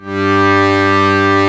G1 BUTTON -L.wav